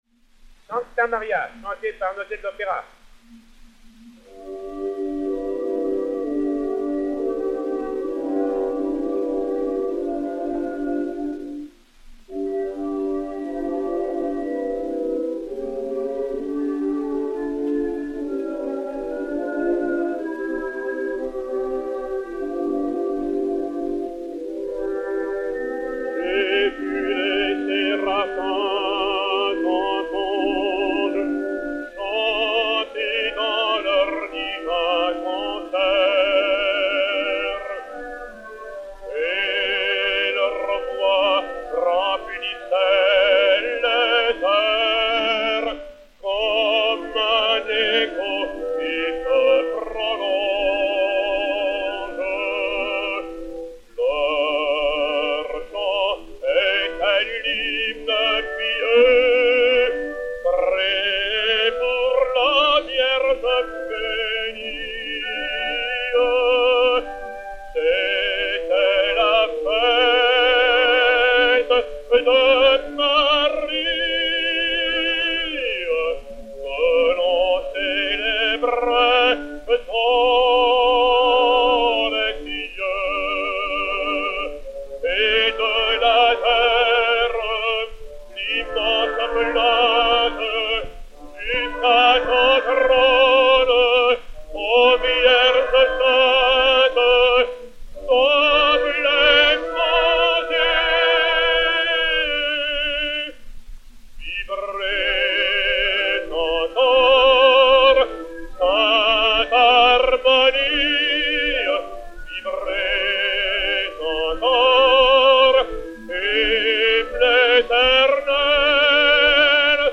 Hymne
Orchestre